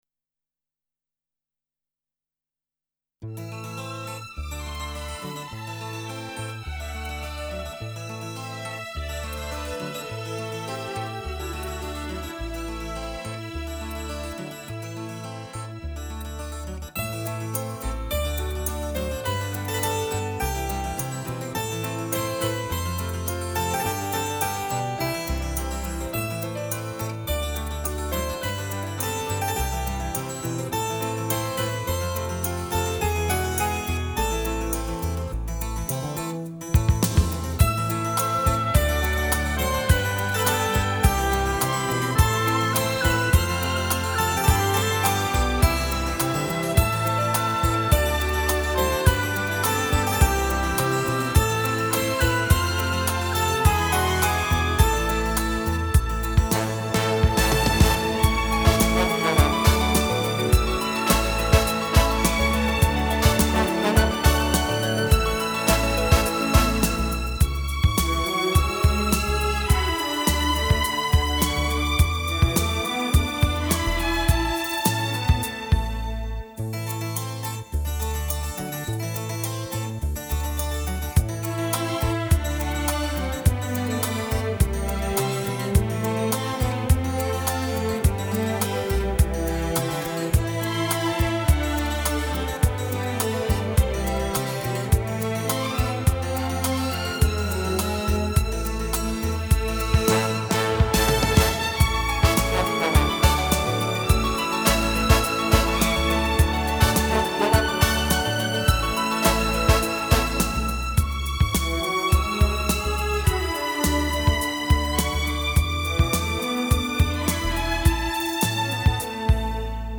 清新华丽，瑰丽浪漫迷人的情调音乐使者。